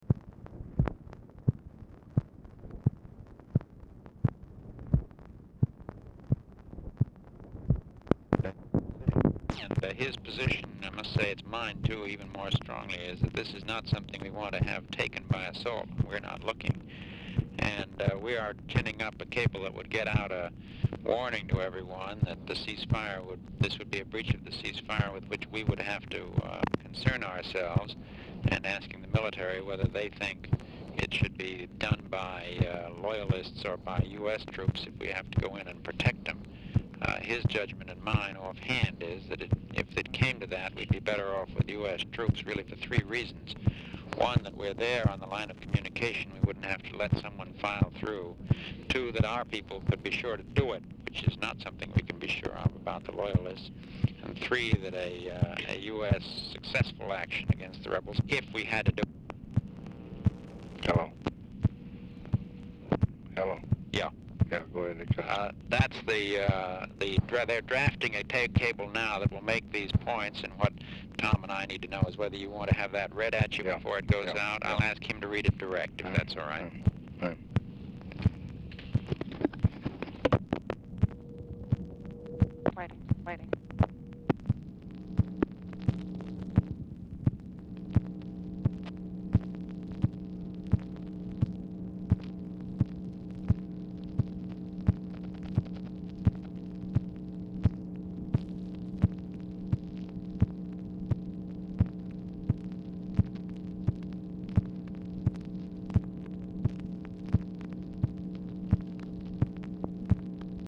RECORDING STARTS AFTER CONVERSATION HAS BEGUN; RECORDING IS BRIEFLY INTERRUPTED
Format Dictation belt
Specific Item Type Telephone conversation Subject Defense Diplomacy Latin America